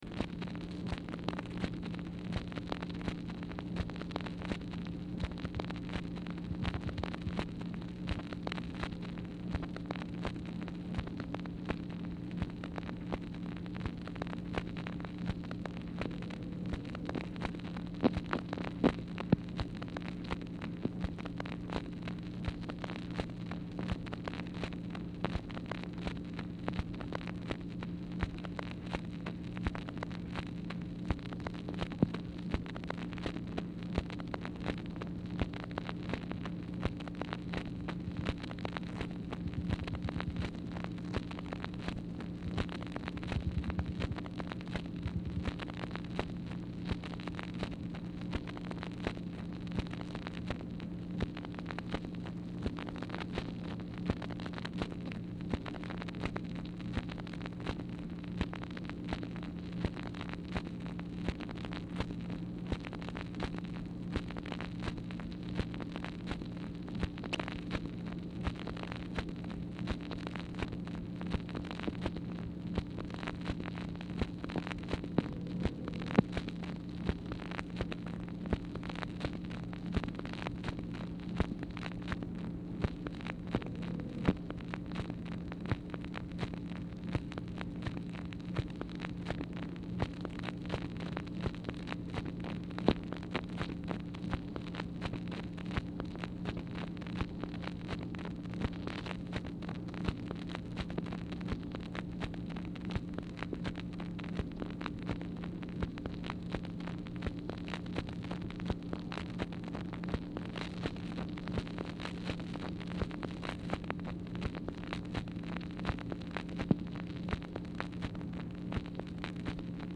Telephone conversation # 2904, sound recording, MACHINE NOISE, 4/8/1964, time unknown | Discover LBJ
Format Dictation belt